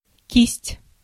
Ääntäminen
Synonyymit pinceau Ääntäminen France: IPA: /bʁɔs/ Haettu sana löytyi näillä lähdekielillä: ranska Käännös Ääninäyte Substantiivit 1. щётка {f} (štšotka) Muut/tuntemattomat 2. кисть {f} (kist) Suku: f .